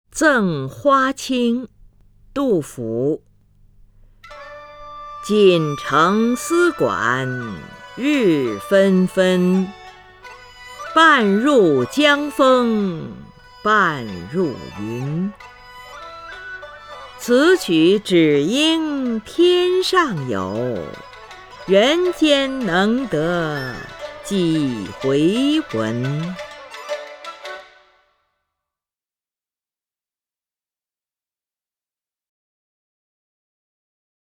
林如朗诵：《赠花卿》(（唐）杜甫) （唐）杜甫 名家朗诵欣赏林如 语文PLUS